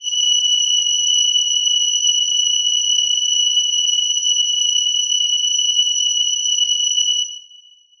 Choir Piano
F#7.wav